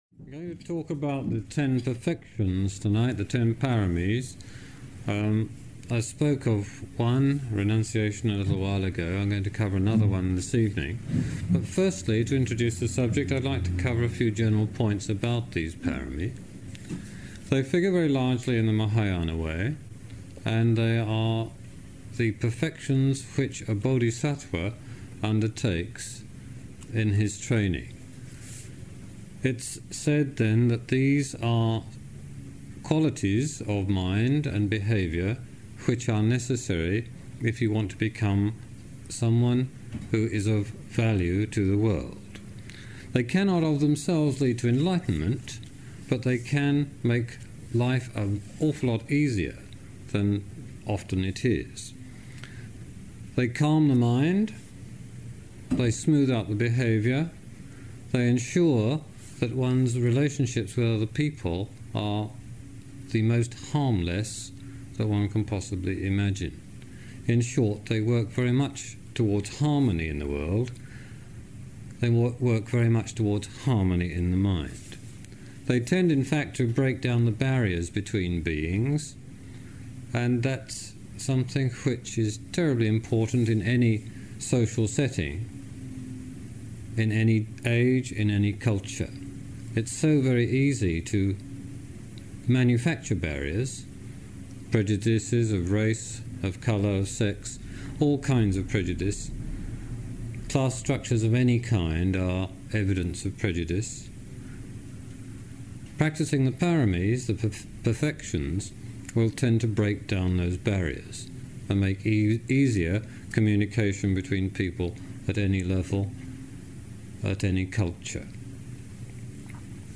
This talk was given in May 1984